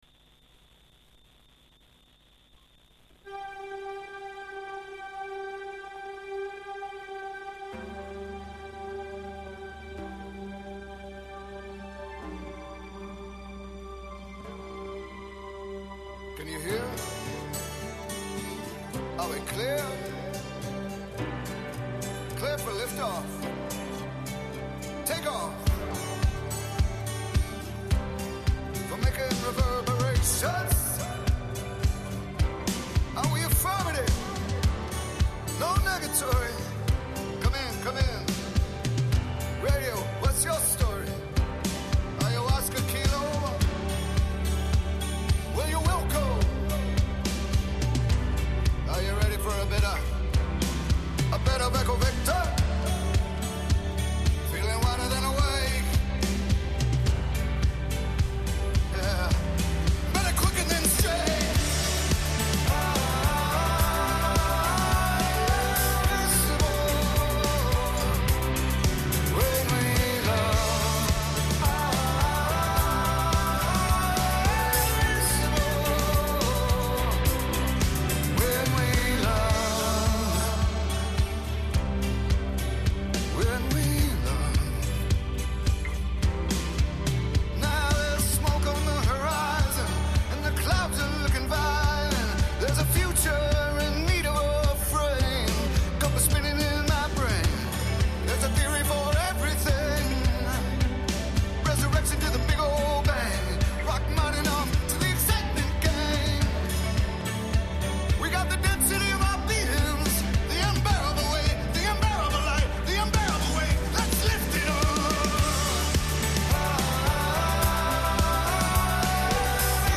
God chats, good sounds and open conversations that bring a point of difference.